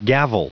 Prononciation du mot gavel en anglais (fichier audio)
Prononciation du mot : gavel